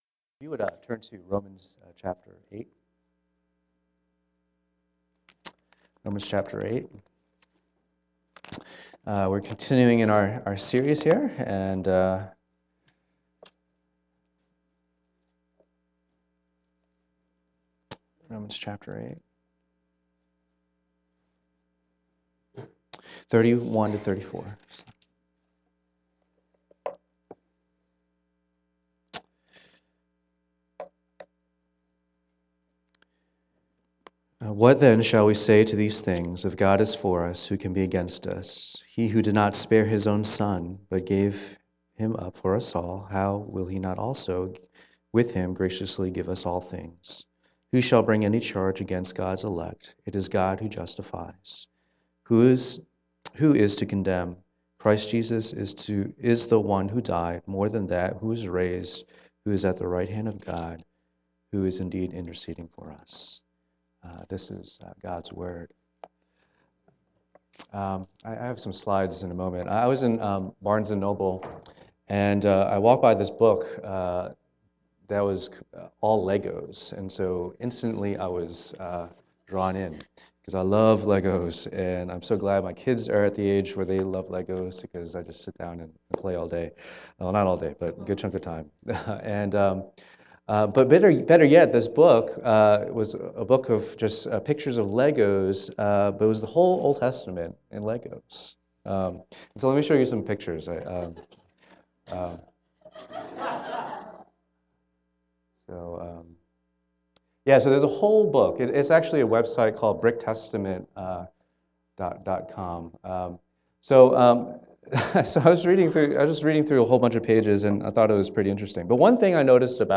Passage: Romans 8:31-34 Service Type: Lord's Day